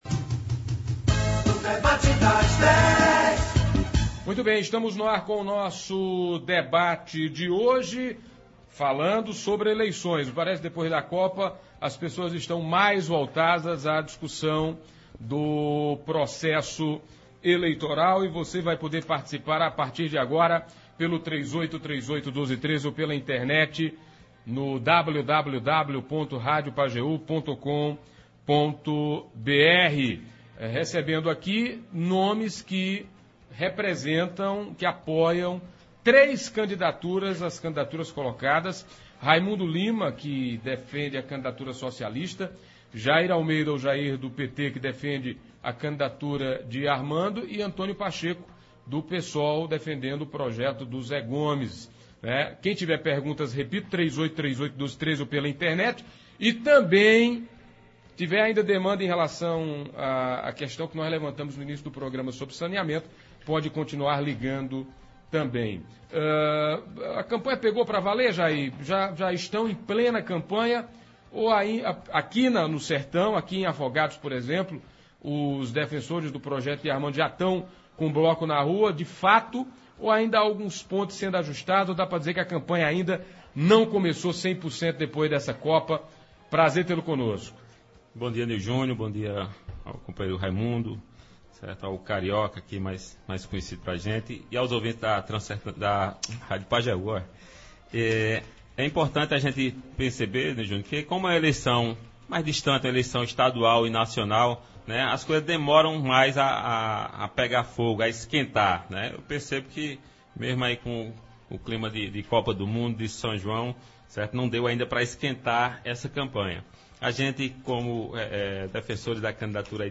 Após a Copa do Mundo agora é hora de voltar às atenções para o processo eleitoral que já caminha a todo vapor. Dando início aos debates políticos, hoje nos estúdios da Pajeú, representantes que apoiam três candidaturas.